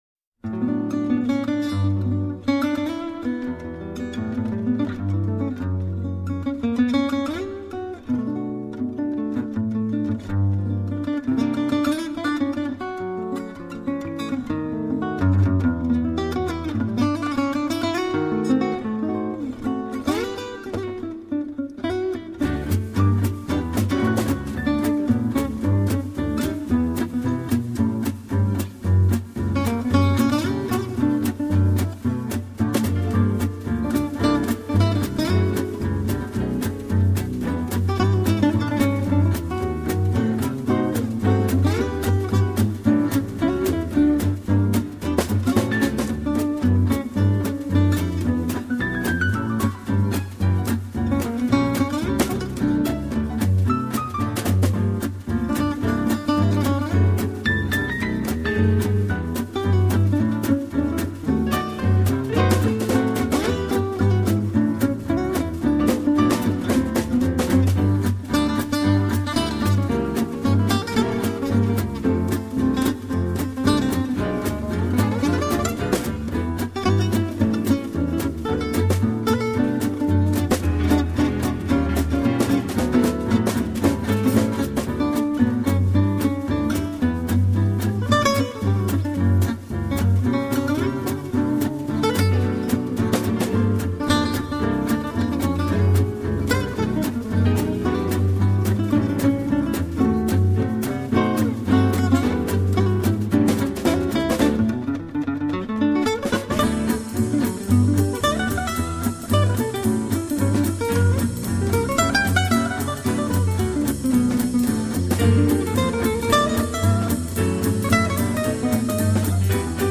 jazz manouche